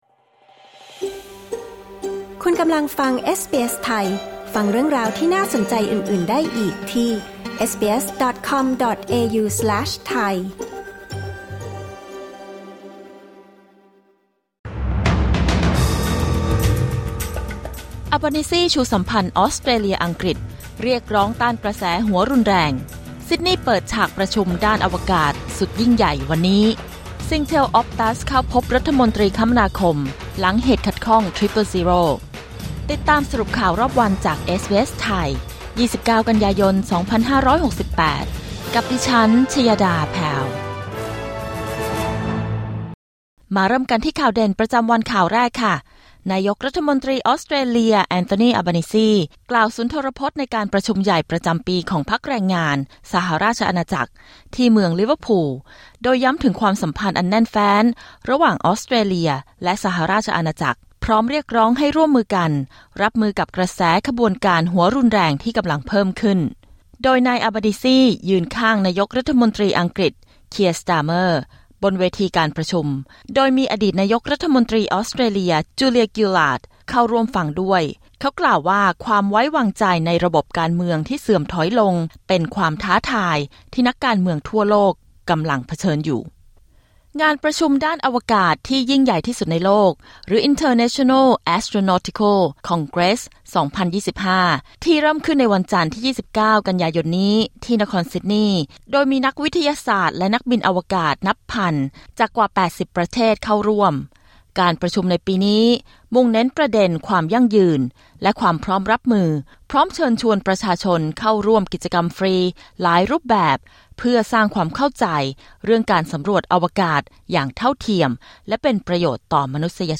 สรุปข่าวรอบวัน 29 กันยายน 2568